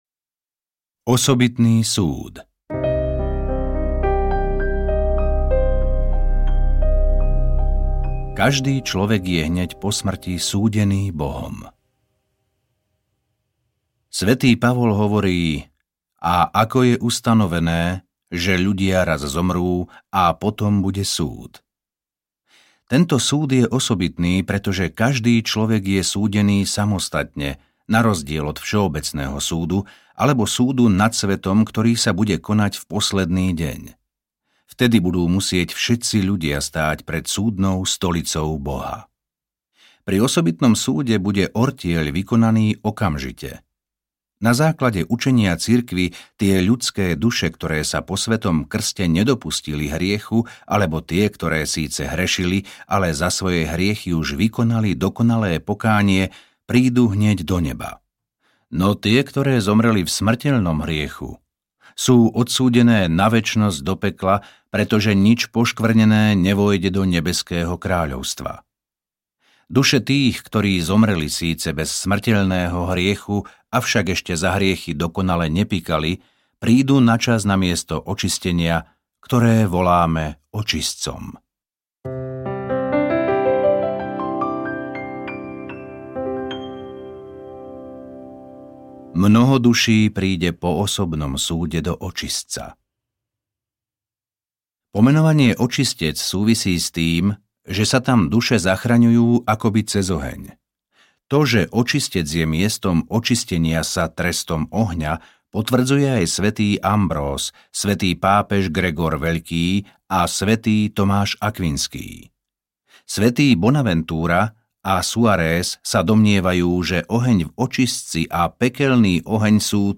Utrpenie a radosti očistca audiokniha
Ukázka z knihy